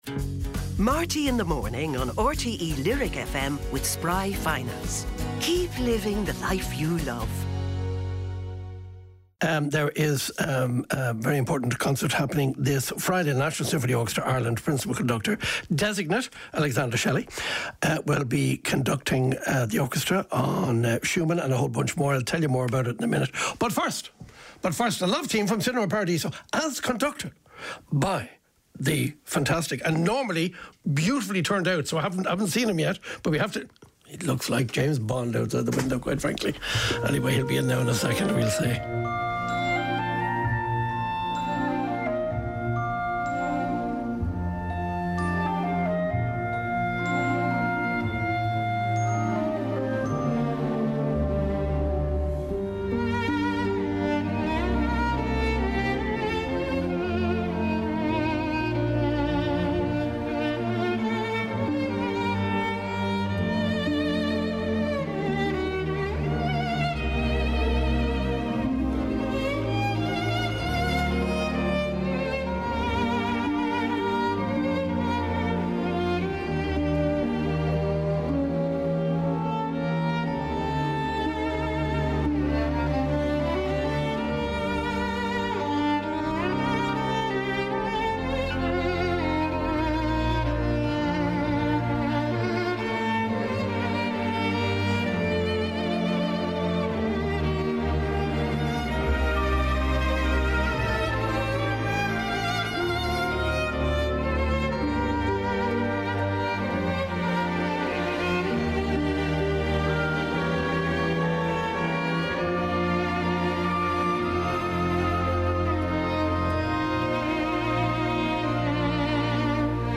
Conductor Alexander Shelley is in studio!